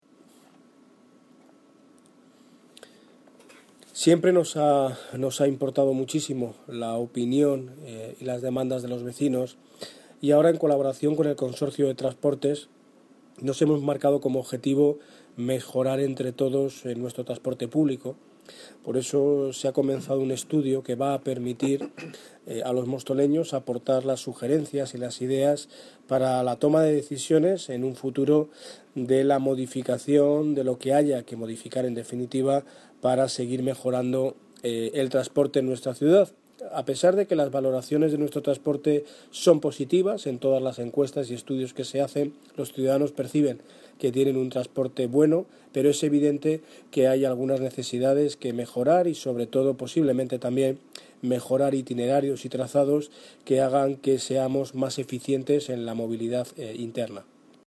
Audio - Daniel Ortiz (Alcalde de Móstoles) Sobre mejoras transporte